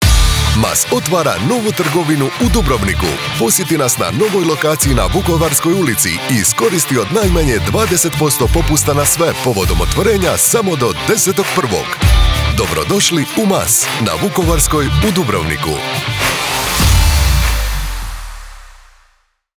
Radio Imaging
I am a professional Croatian voice-over artist, speaker, narrator and producer with over 20 years of experience with my own studio.
Middle-Aged
BassDeep
WarmAuthoritativeConversationalCorporateFriendly